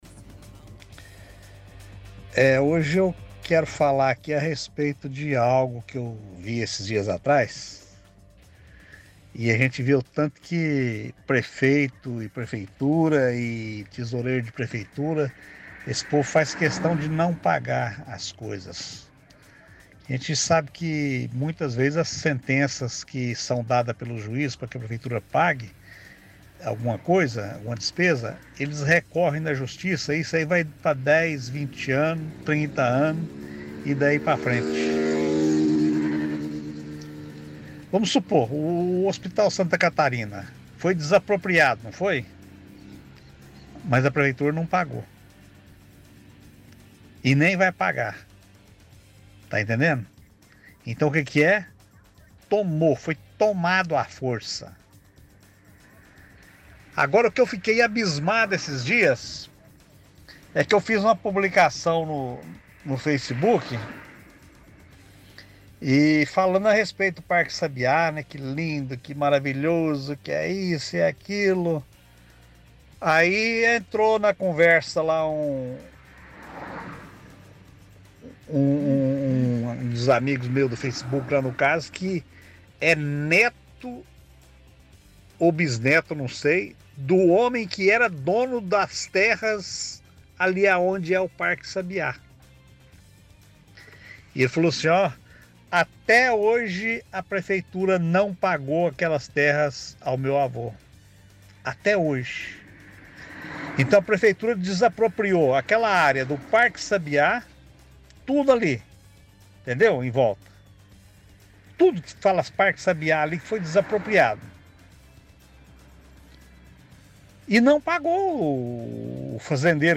– Ouvinte reclama que prefeitura sempre recorre na justiça quando precisa pagar multas ou deveres.